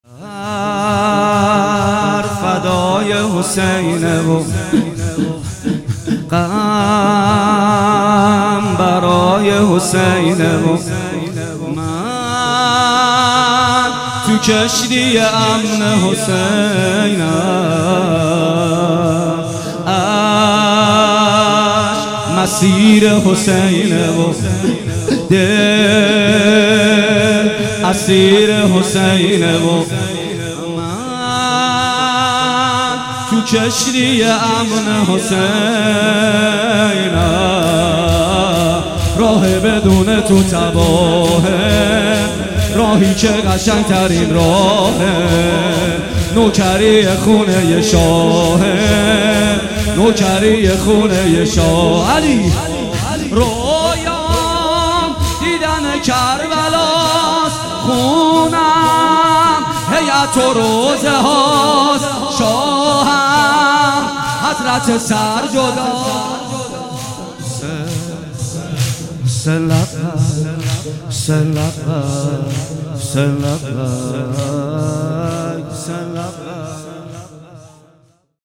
محرم الحرام 1441 هیئت ریحانه النبی تهران